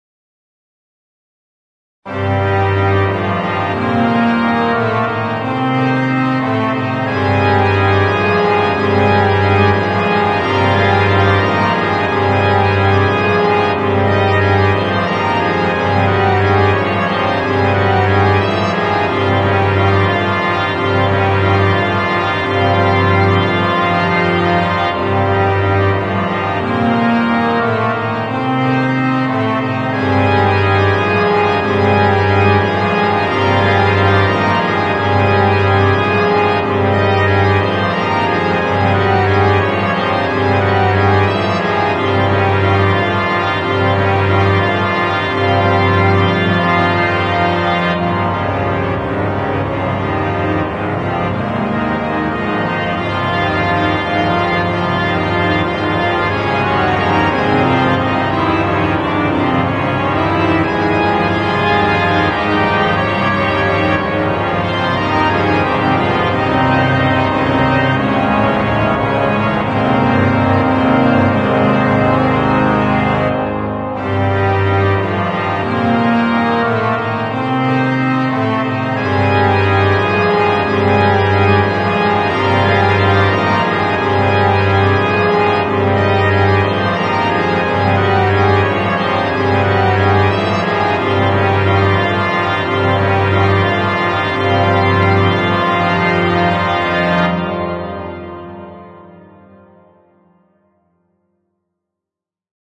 Church Organ: